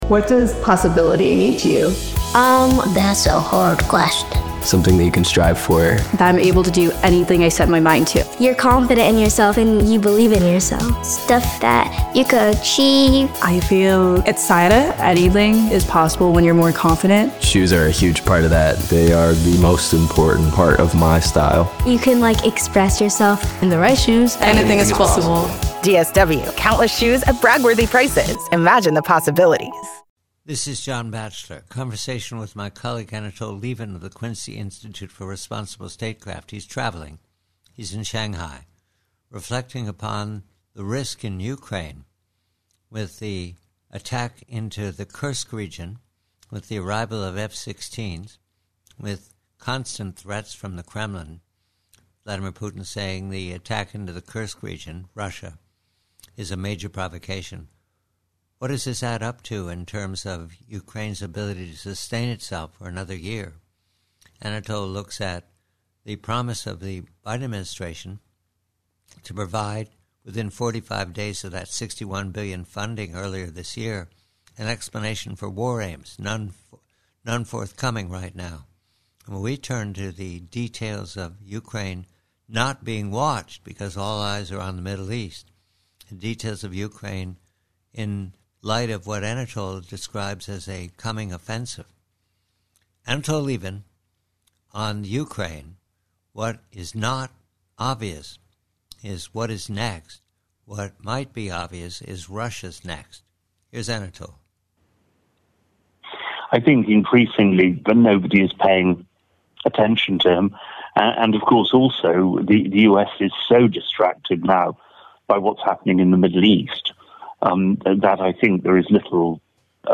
PREVIEW: RUSSIA: UKRAINE: Conversation with colleague Anatol Lieven of the Quincy Institute regarding the fact that the US is not paying close attention now, distracted by the Middle East, and that Moscow is said to be readying a last push -- perhaps before the US Election.